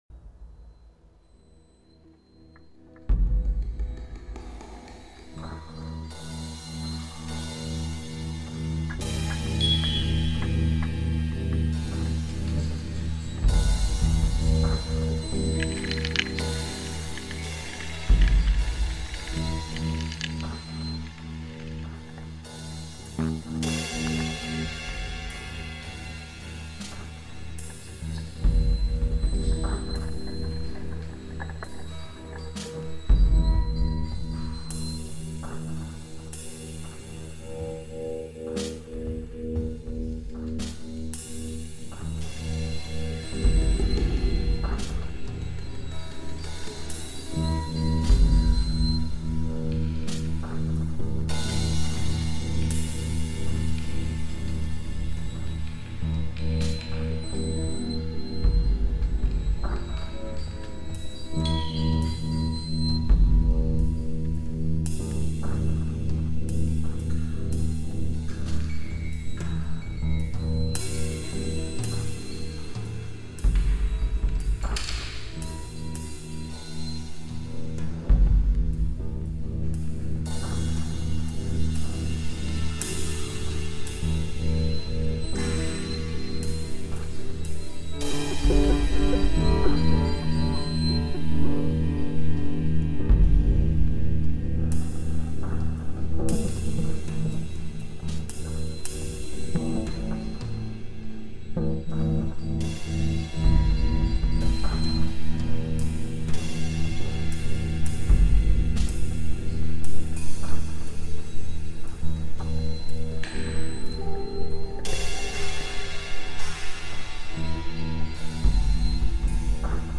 Compositions sonores